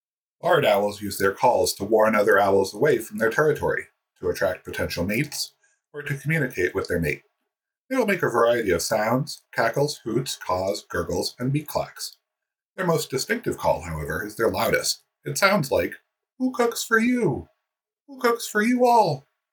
Barred Owl Narration